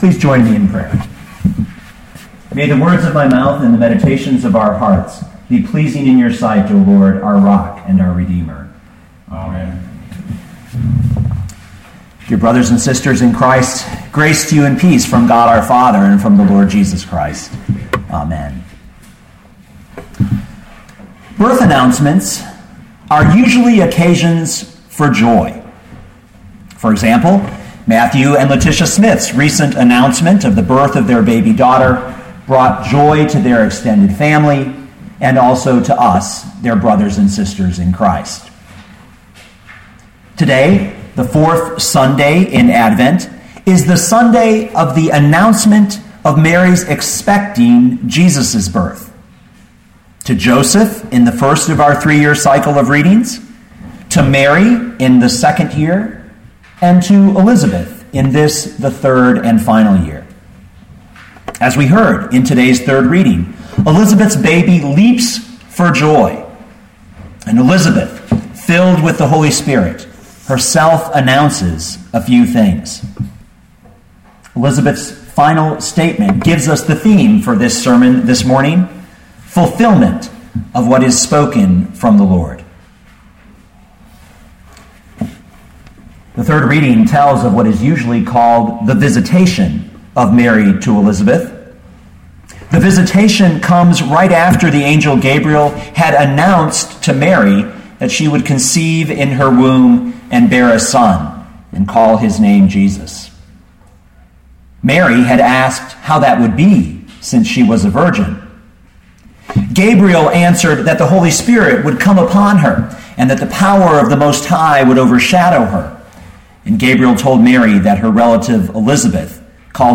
2012 Luke 1:39-45 Listen to the sermon with the player below, or, download the audio.